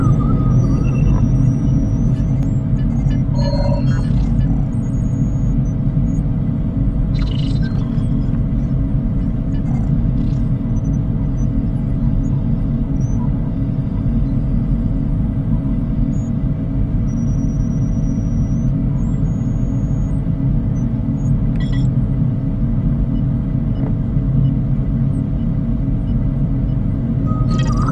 bridge2.ogg